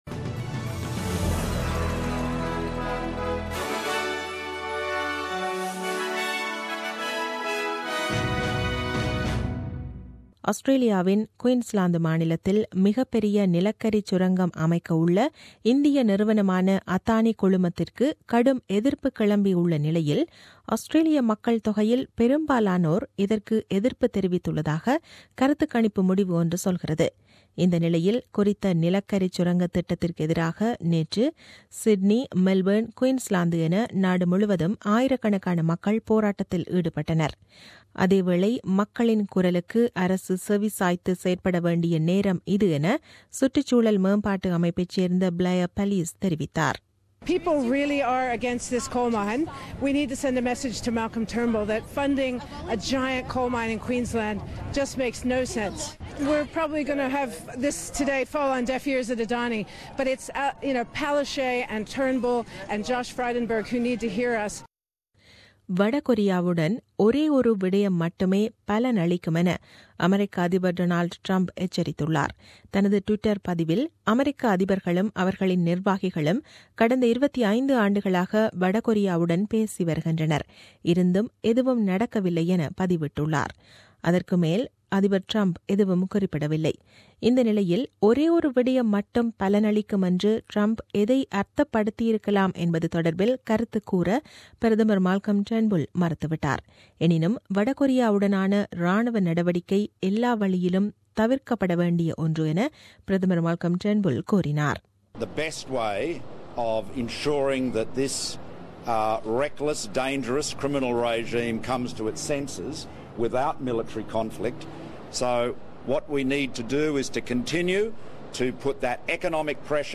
The news bulletin aired on 08 October 2017 at 8pm.